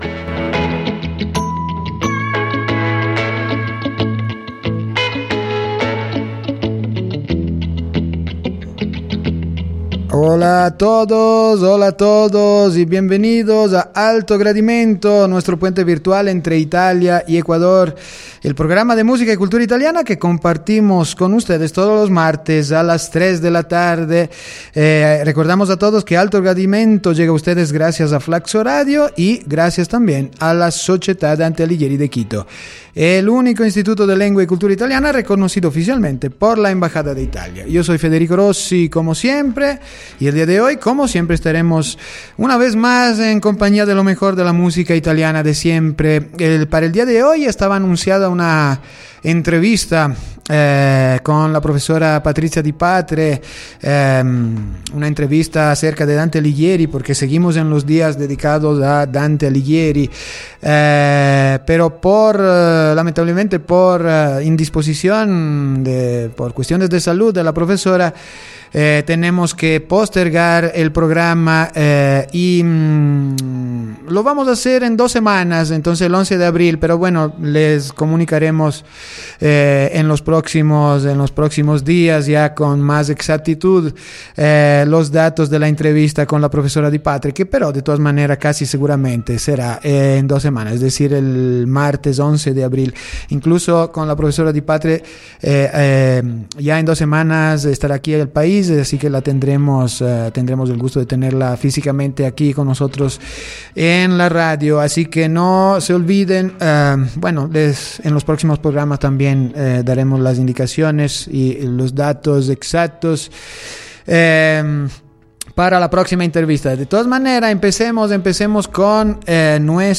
canción de autor italiana